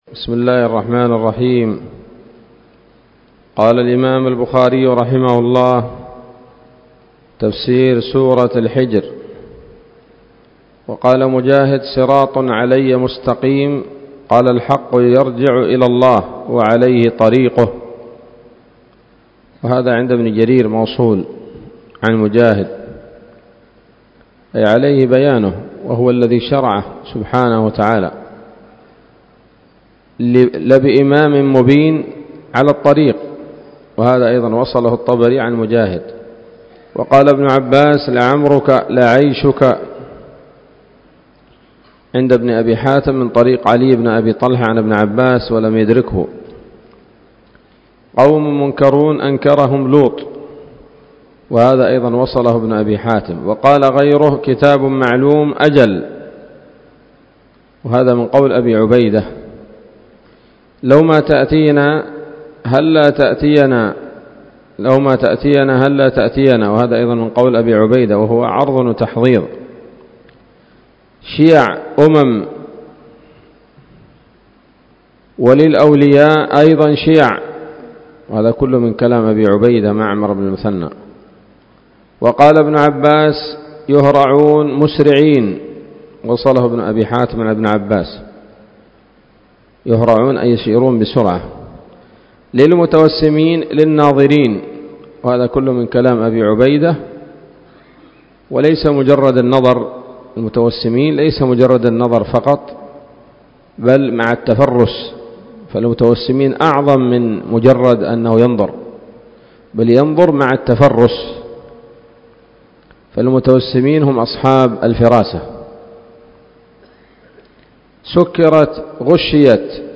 الدرس السادس والأربعون بعد المائة من كتاب التفسير من صحيح الإمام البخاري